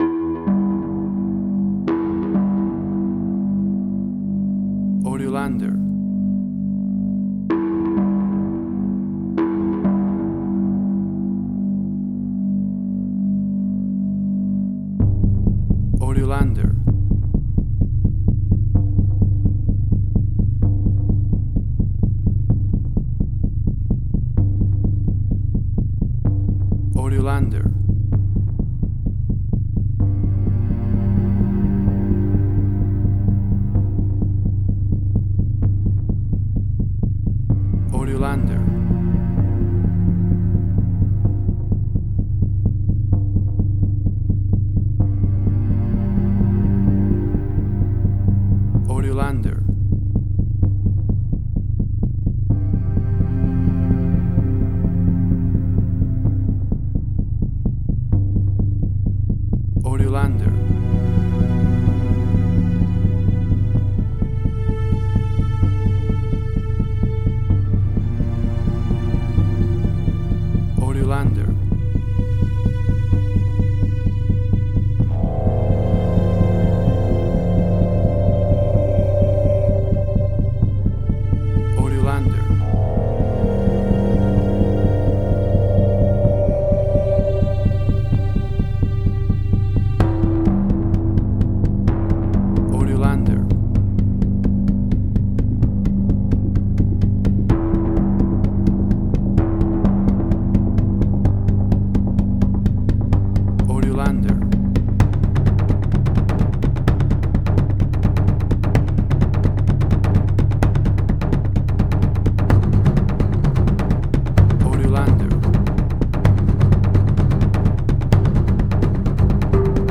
Post-Electronic.
Tempo (BPM): 128